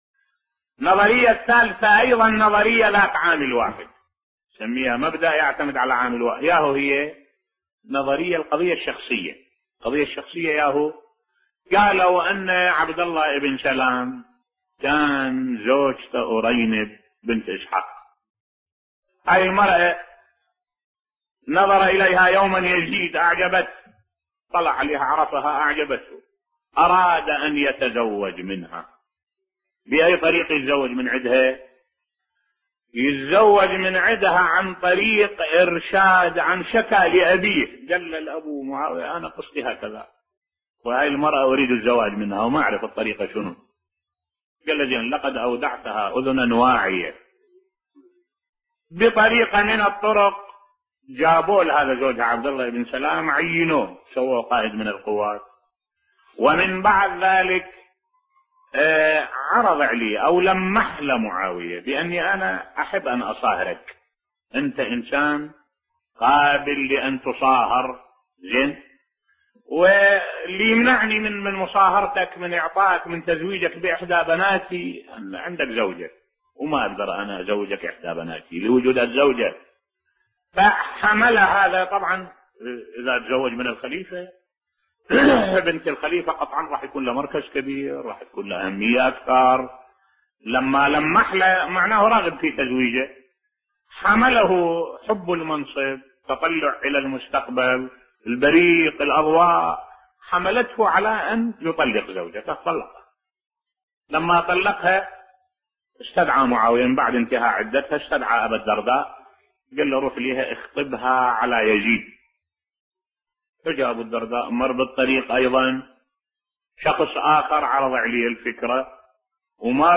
ملف صوتی لا أجدني متفاعلا بالقصة المنسوبة للحسين حول زواجه بأرينب بصوت الشيخ الدكتور أحمد الوائلي